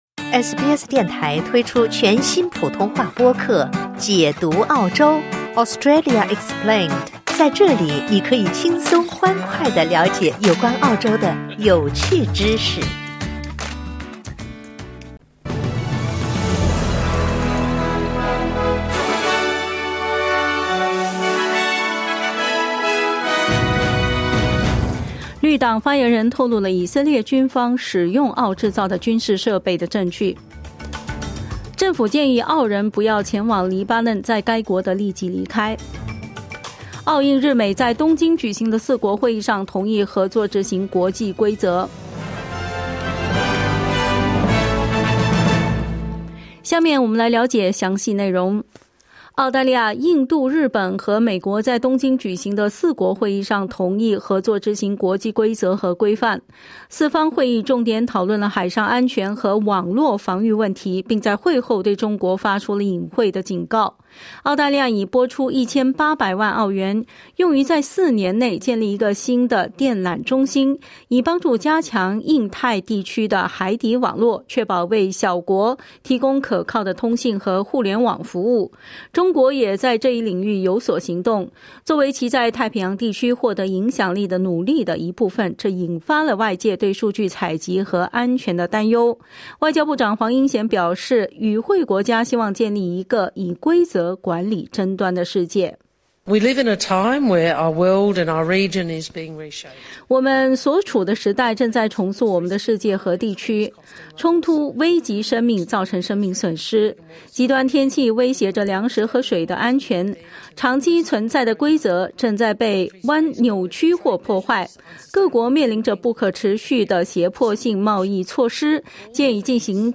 SBS早新闻（2024年7月30日）